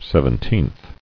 [sev·en·teenth]